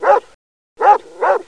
SOUND\DOG6.WAV